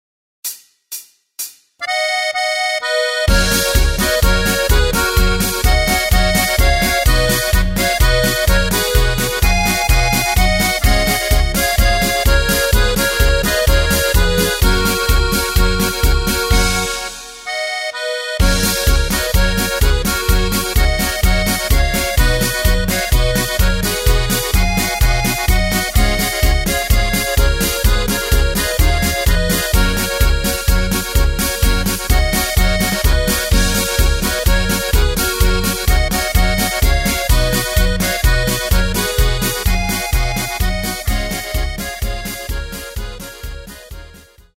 Takt:          2/4
Tempo:         127.00
Tonart:            F#
Schweizer Polka aus dem Jahr 2013!
Playback mp3 Demo